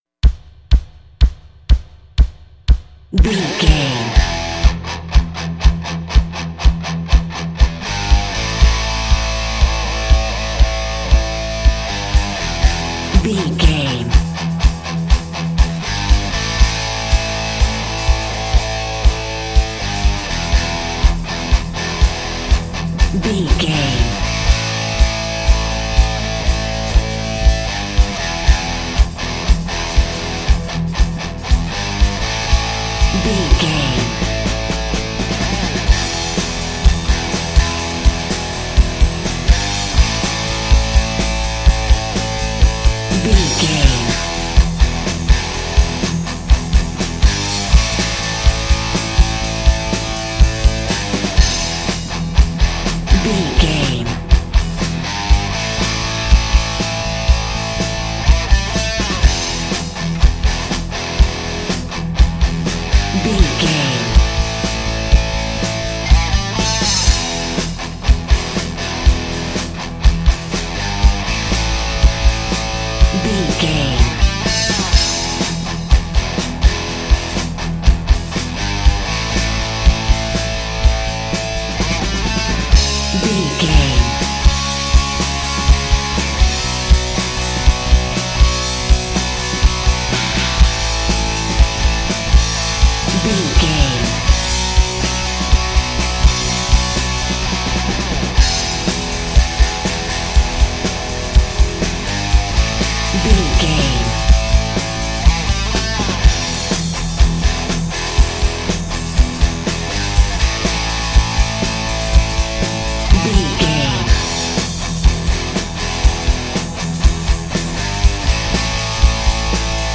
Aeolian/Minor
drums
electric guitar
hard rock
bass
aggressive
energetic
intense
nu metal
alternative metal